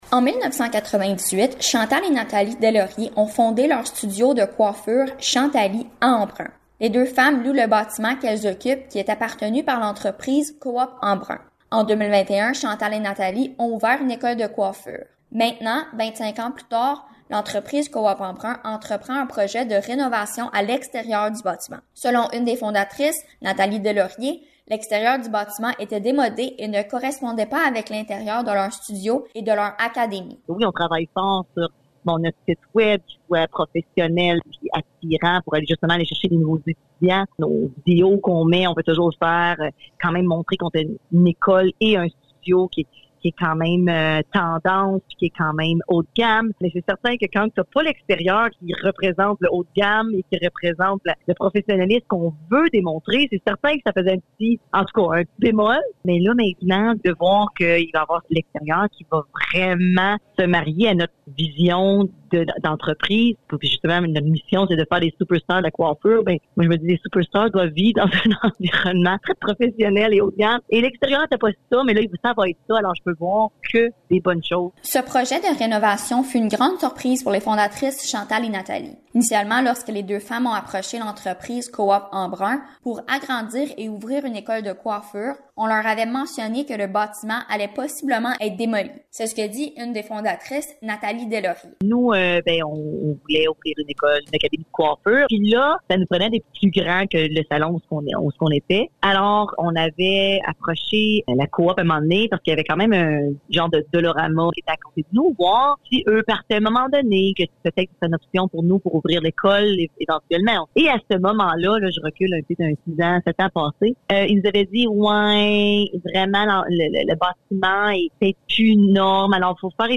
Reportage-renovation.mp3